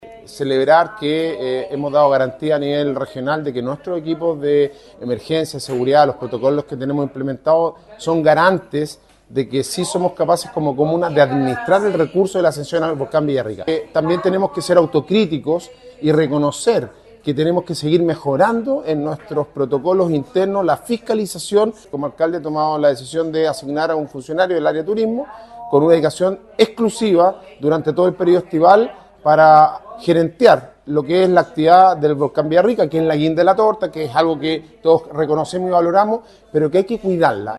Alcalde-Sebastian-Alvarez-valora-el-trabajo-hecho-pero-suma-mas-desafios-1-1.mp3